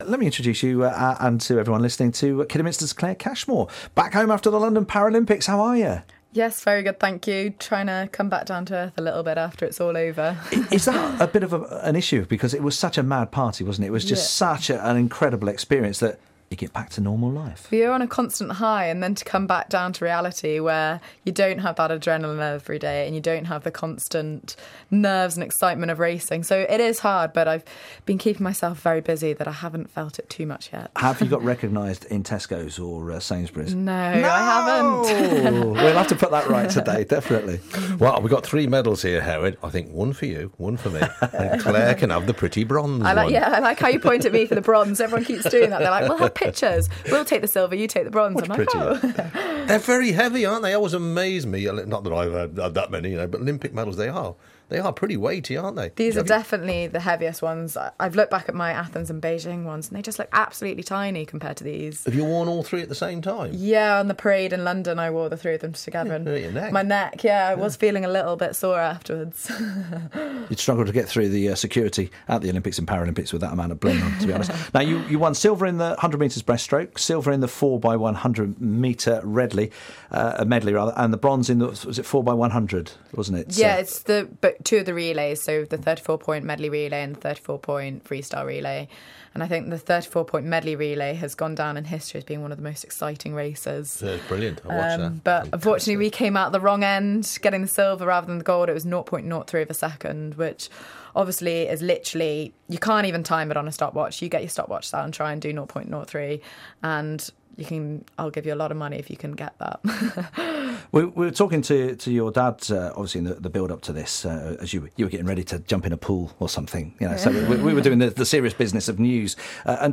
Hear Kidderminster's Paralympian triple medallist in conversation